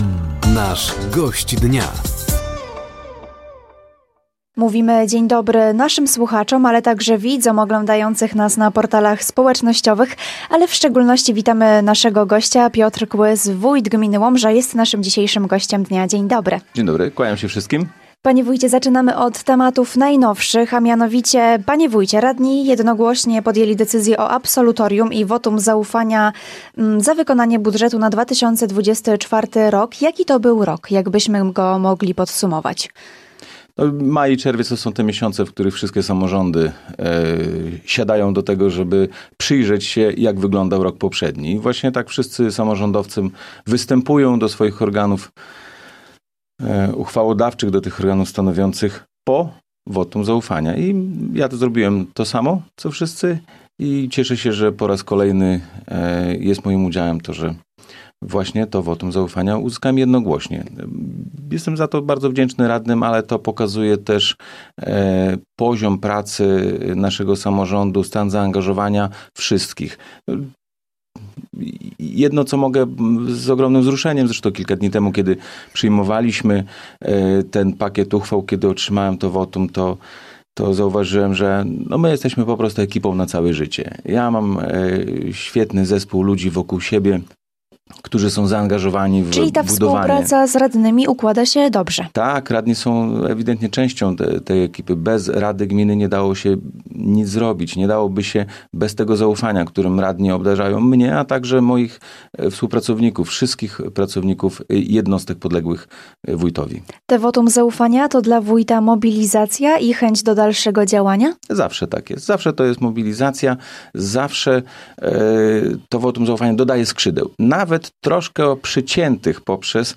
Gościem Dnia Radia Nadzieja był dzisiaj Piotr Kłys wójt gminy Łomża. Tematem rozmowy było absolutorium dla wójta, tegoroczne wyzwania samorządu i realizowane inwestycje.